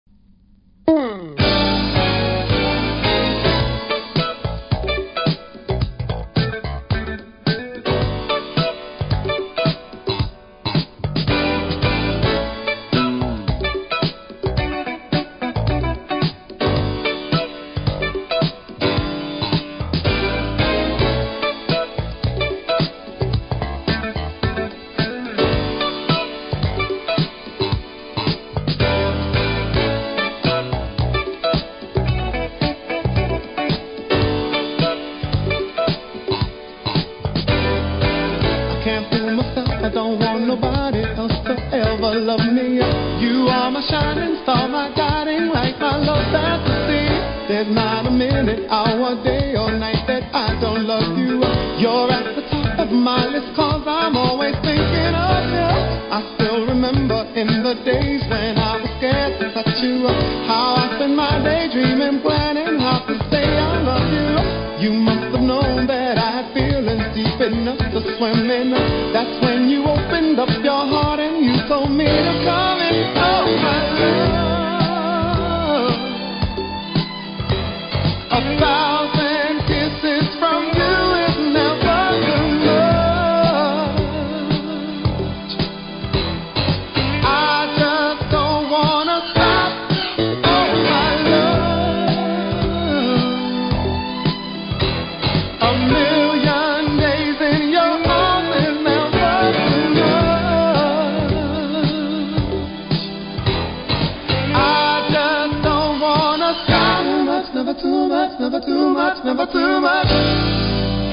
多少キズありますが音には影響せず良好です。